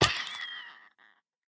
Minecraft Version Minecraft Version latest Latest Release | Latest Snapshot latest / assets / minecraft / sounds / mob / turtle / baby / death1.ogg Compare With Compare With Latest Release | Latest Snapshot